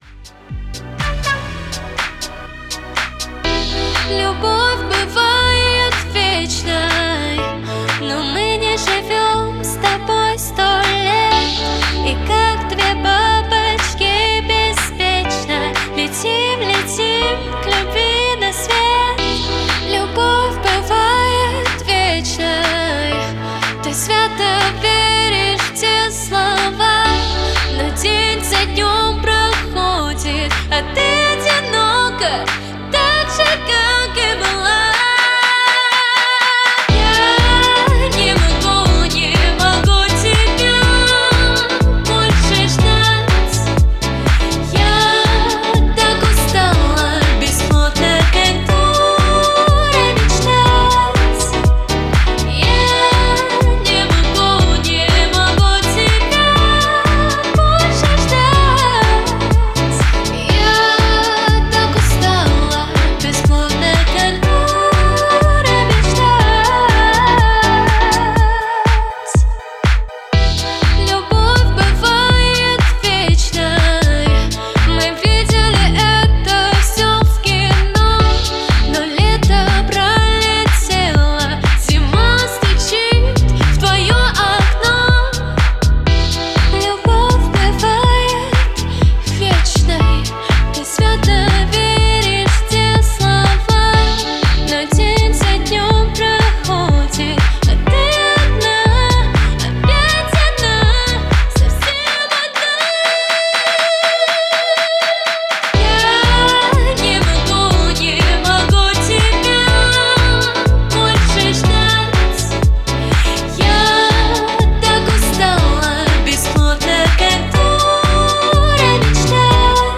Поп-Устала мечтать - женский вокал
барабаны стучат одно и тоже три минуты
На все варианты он говорит - что вы там мудрите, оперу что ли строите? простая диско песня хит...и больше ничего не надо... А я продолжил наступать на горло собственным предпочтениям и еще убрал обьем бочки, поджал весь микс снизу, т е делаю более конвенциональный трек.... типа отлавливаю самых мелких блох....Теперь так звучит -